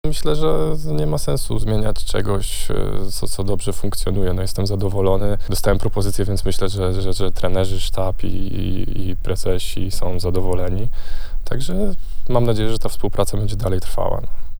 Zapraszamy do obejrzenia krótkiej rozmowy, którą przeprowadziliśmy z zawodnikiem tuż po podpisaniu kontraktu: